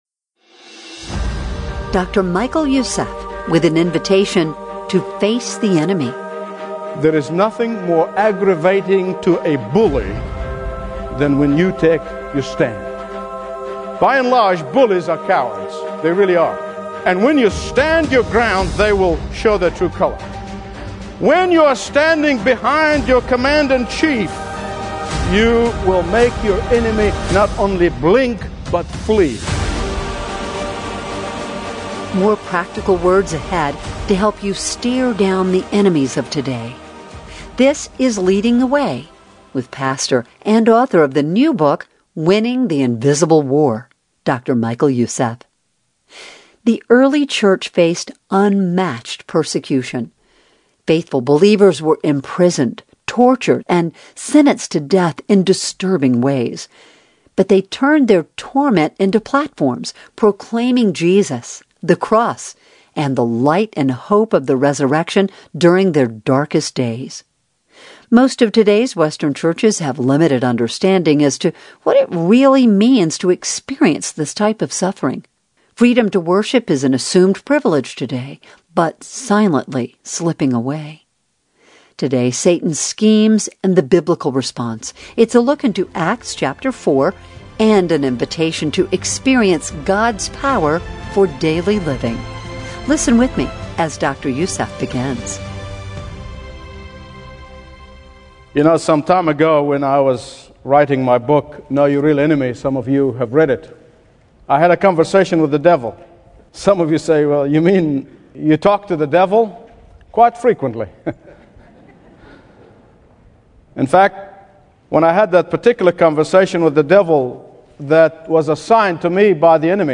Preaching from Acts 4, he shows how Peter and the apostles responded to persecution with boldness, unity, and Spirit-filled courage. Rather than cowering, they turned suffering into a pulpit and opposition into opportunity.